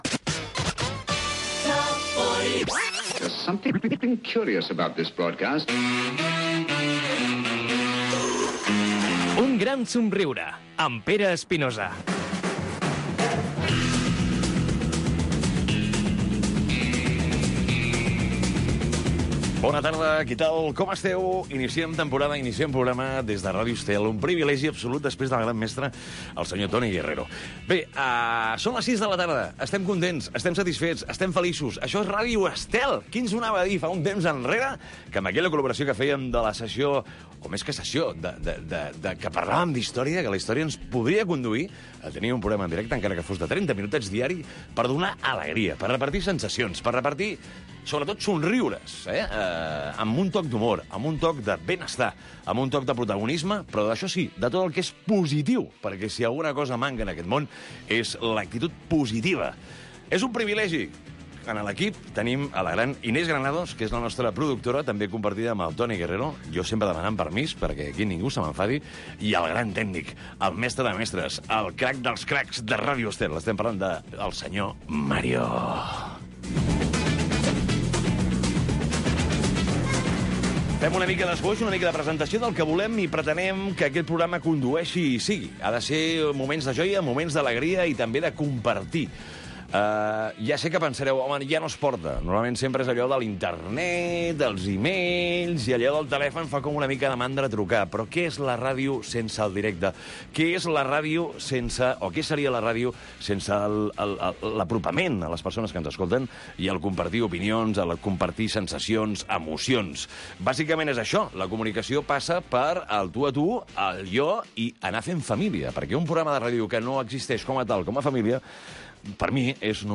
Careta del programa
Gènere radiofònic Entreteniment